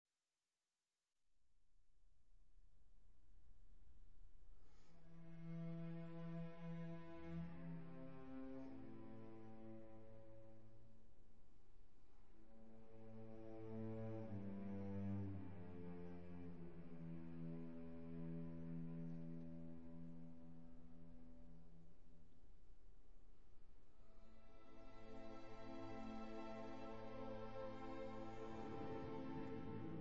tenore
• registrazione sonora di musica